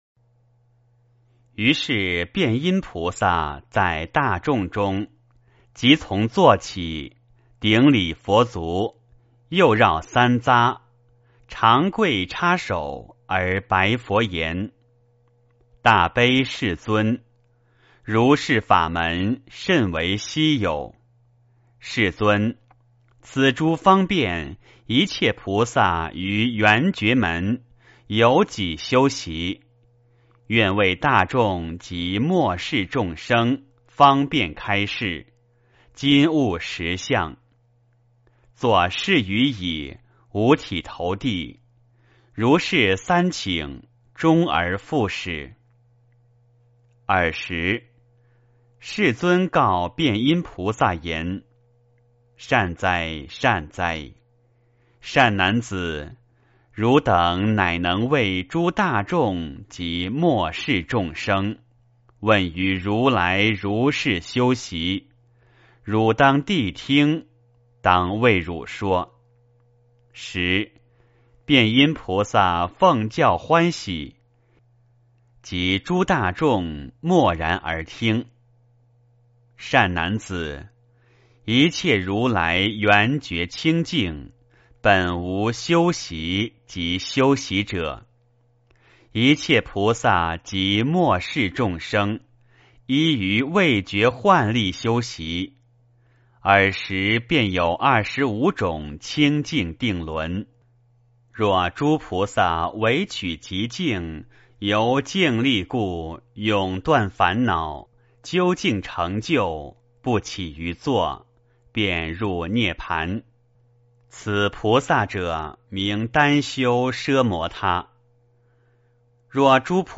圆觉经-08辨音菩萨 - 诵经 - 云佛论坛